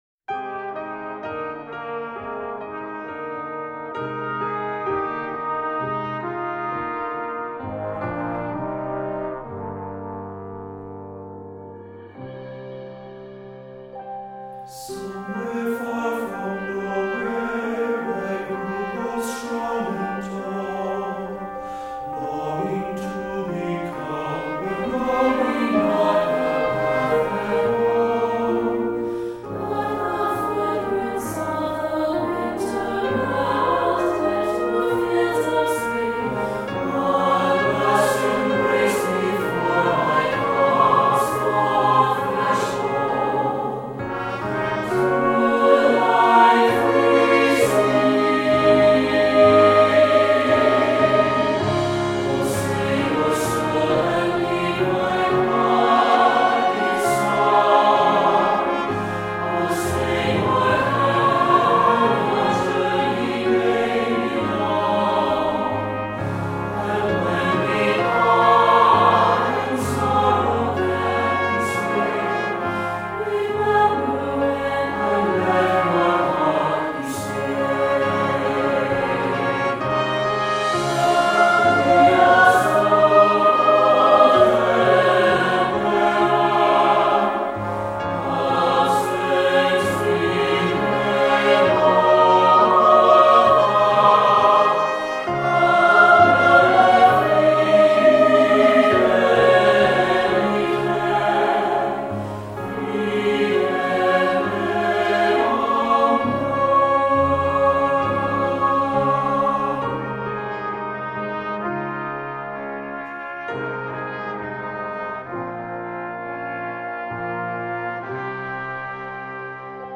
Voicing: SSAB and Piano